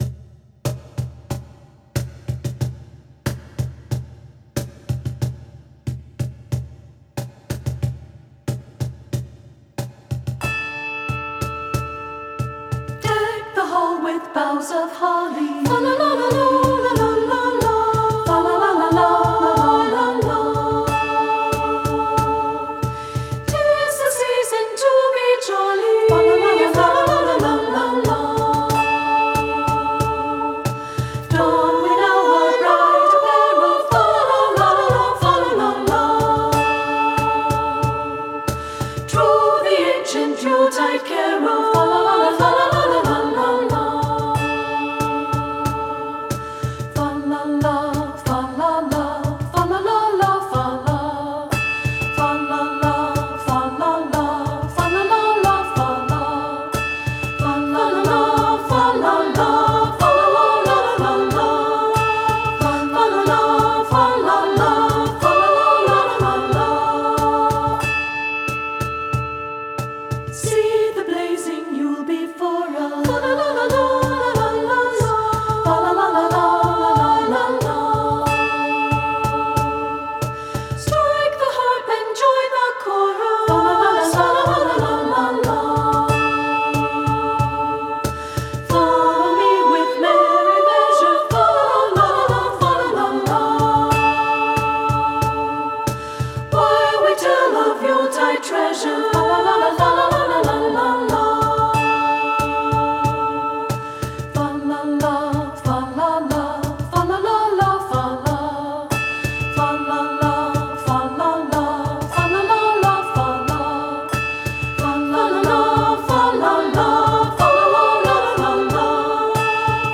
SA Voices, a cappella with Hand Drum and Handbells
• Soprano
• Alto
• Hand Drum
• Handbells
Studio Recording
Ensemble: Treble Chorus
Key: D minor
Tempo: Vibrant! (q = 92)
Accompanied: A cappella